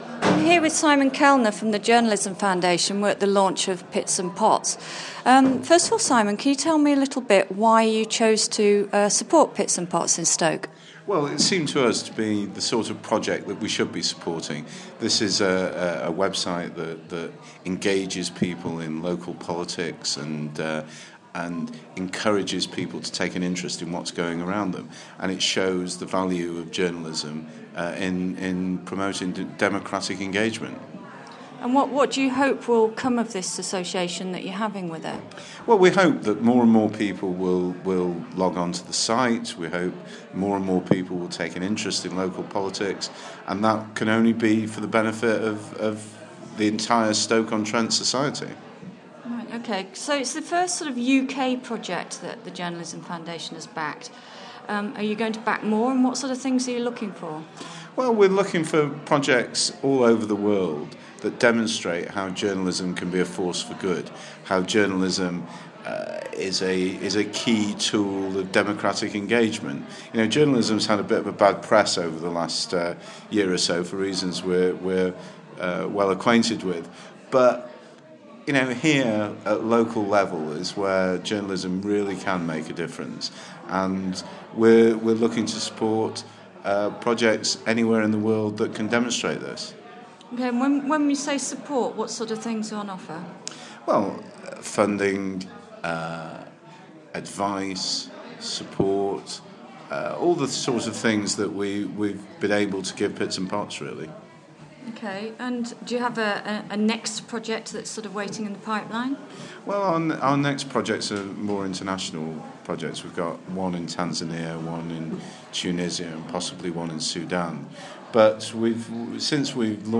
Interview with Simon Kelner from the Journalism Foundation